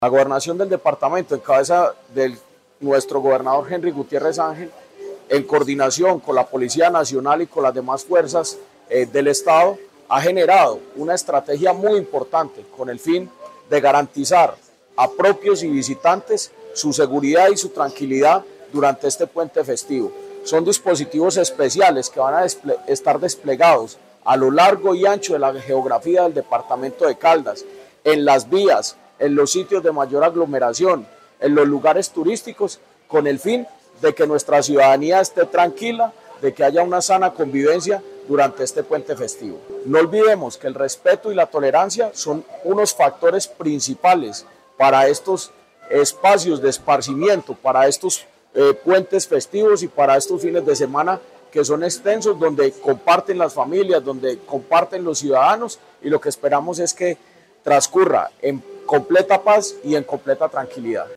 Secretario de Gobierno de Caldas, Jorge Andrés Gómez Escudero.